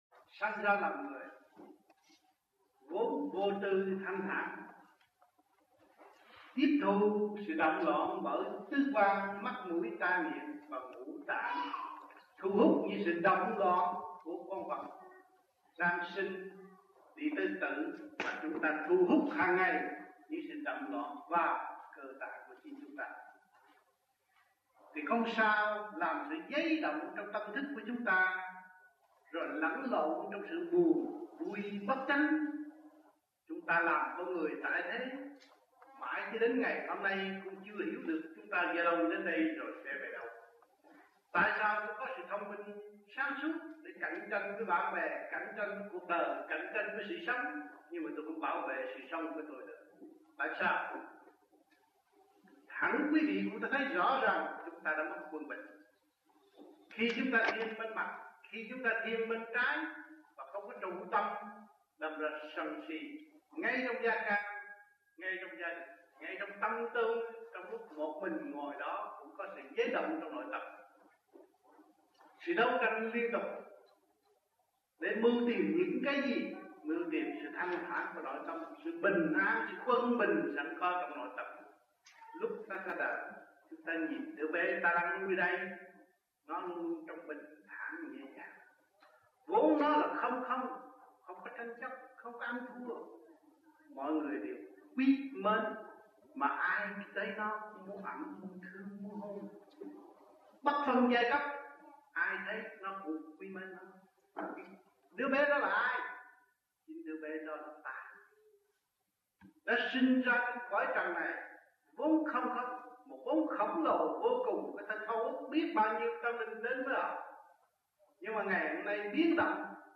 1985-01-06 - MELBOURNE - THUYẾT PHÁP